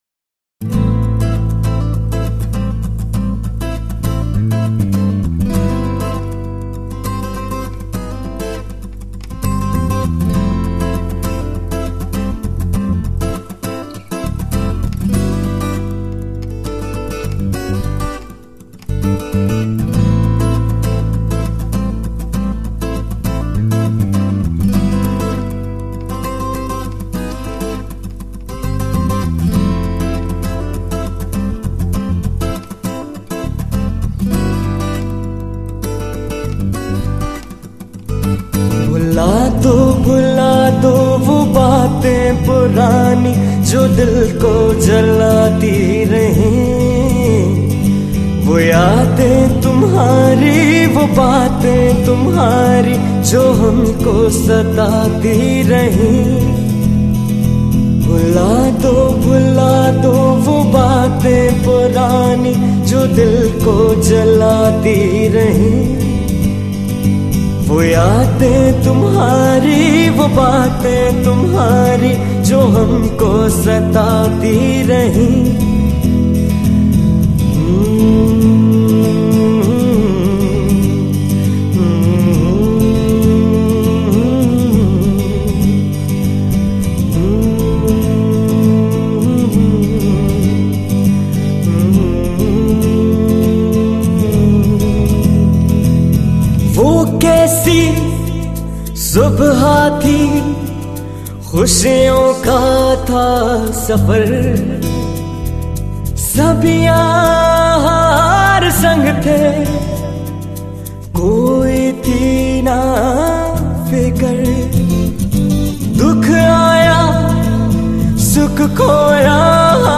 Pakistani Songs